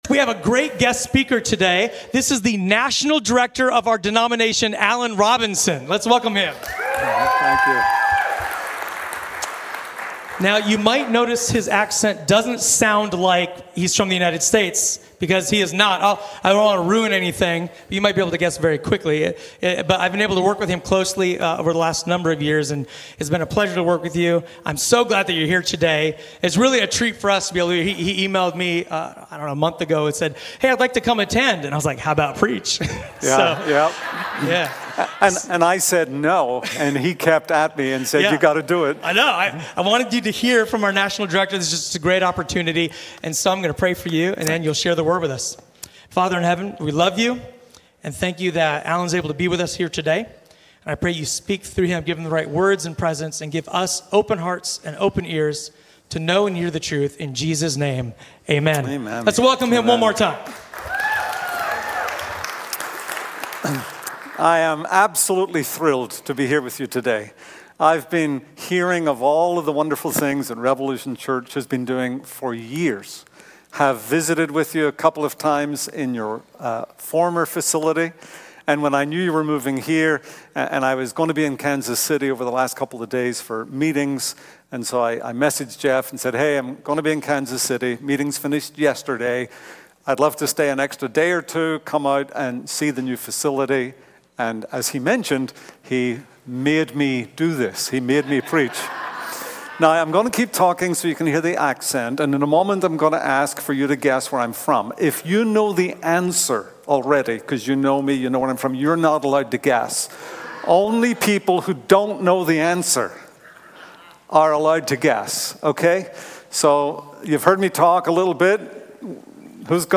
A sermon from the series "Guest."